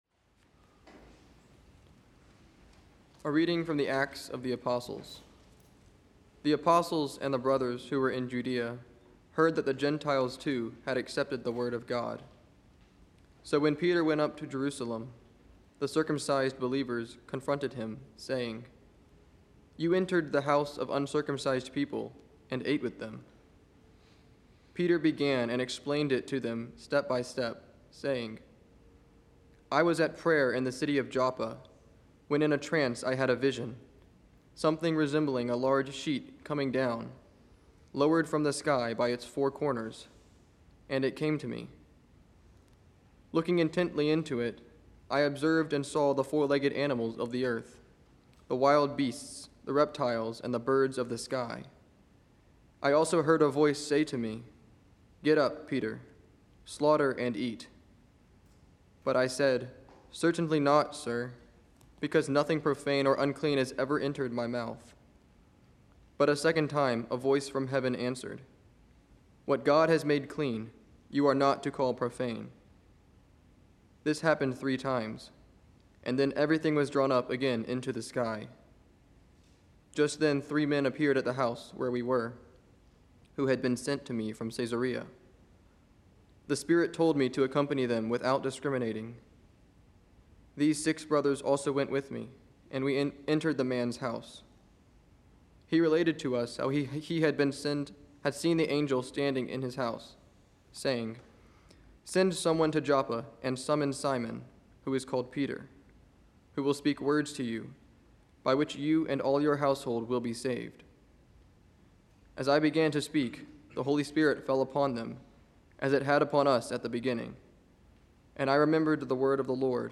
From Our Lady of the Angels Chapel on the EWTN campus in Irondale, Alabama.